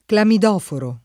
clamidoforo [ klamid 0 foro ]